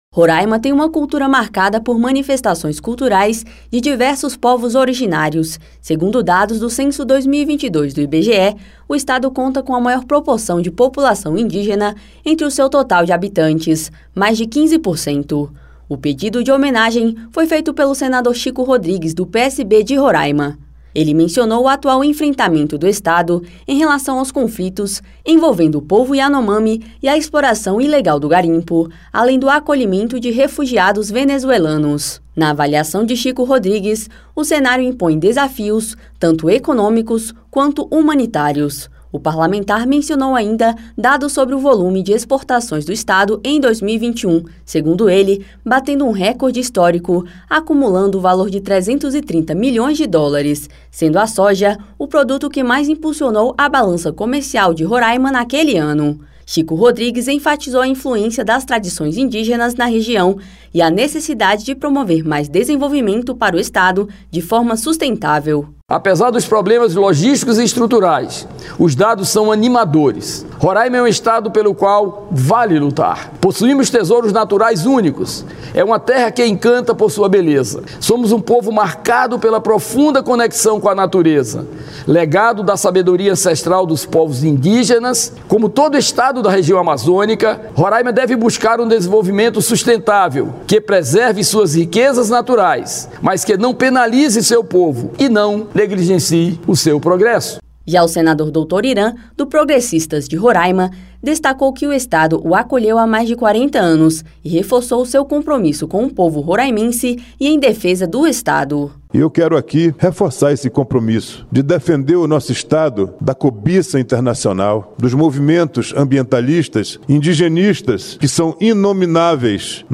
Uma sessão especial do Senado nesta terça (3) comemorou os 35 anos do estado de Roraima e os 80 anos de sua criação como território. O pedido de homenagem foi feito pelo senador Chico Rodrigues (PSB-RR), que destacou a diversidade cultural e a necessidade de promover o desenvolvimento da região. O senador Dr. Hiran (PP-RR) reforçou seu compromisso com o povo roraimense e em defesa do estado.